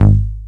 cch_bass_one_shot_short_G#.wav